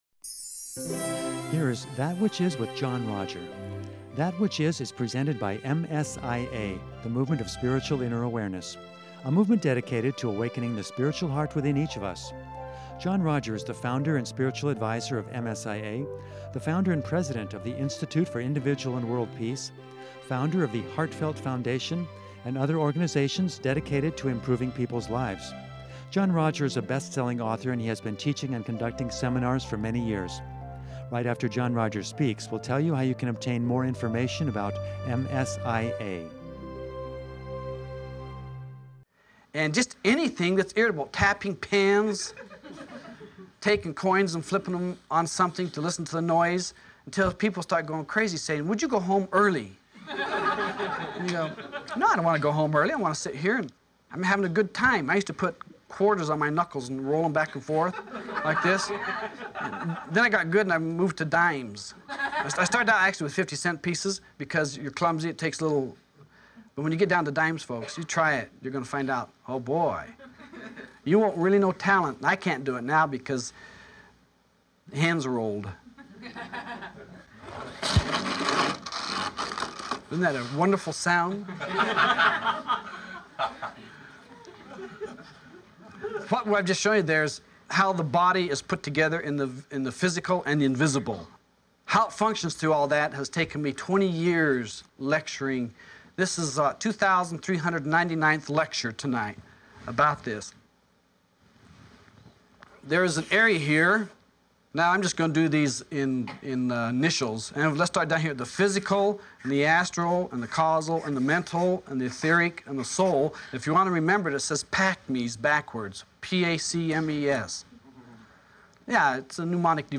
In the second part of this lovely seminar